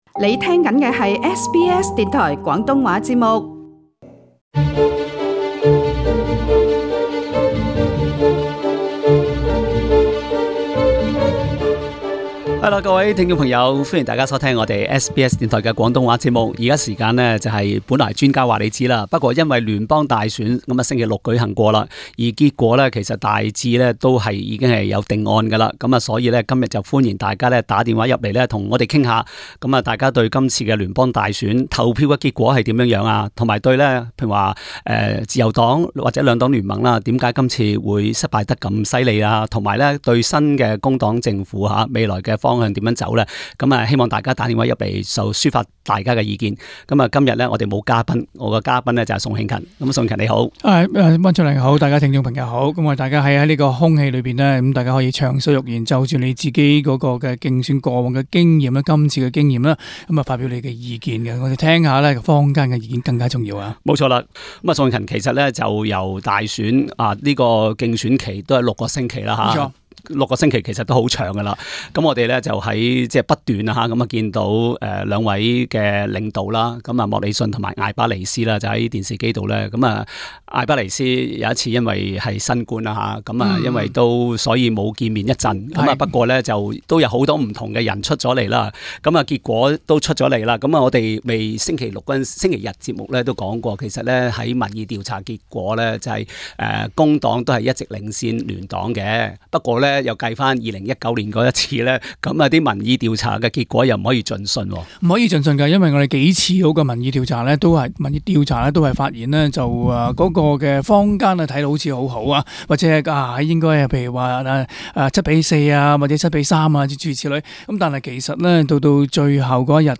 與聽眾暢談 - 本屆大選你為何這樣投票？